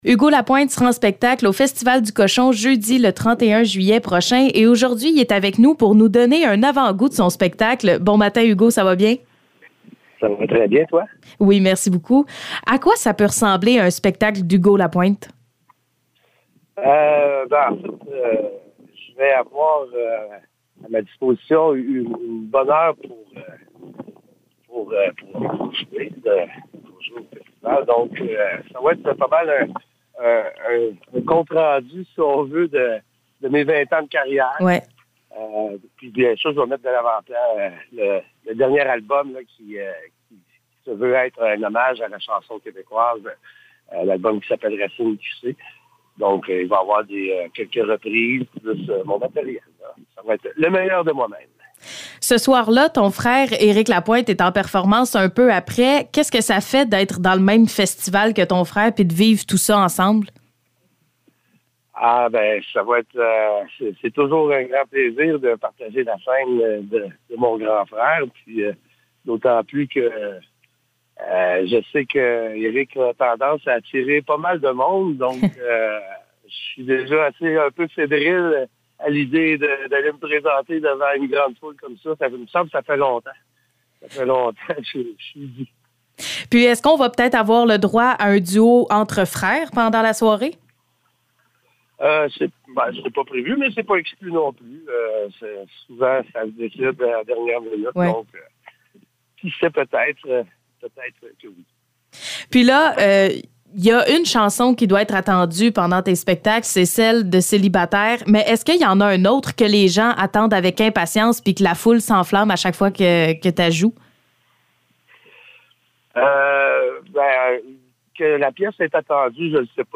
Entrevue avec Hugo Lapointe
Entrevue avec Hugo Lapointe concernant son spectacle du 31 juillet prochain au Festival du cochon de Ste-Perpétue.